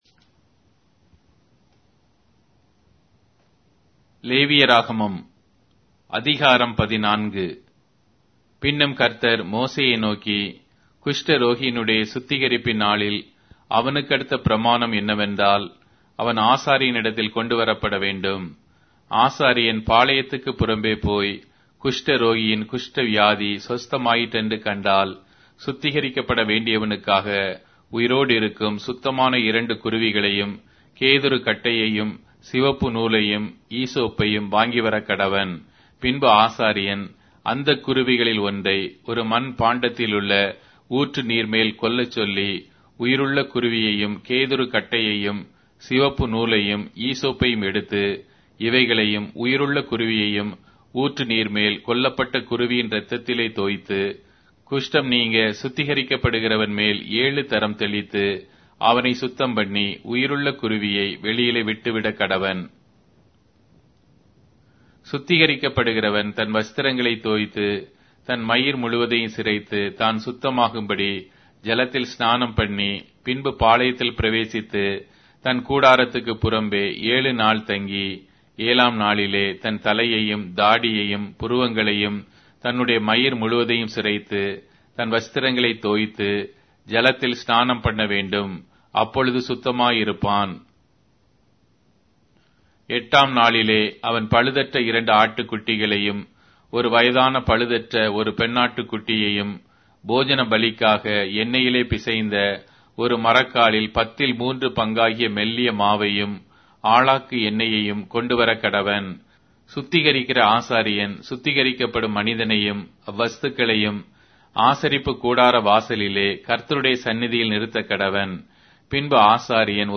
Tamil Audio Bible - Leviticus 25 in Knv bible version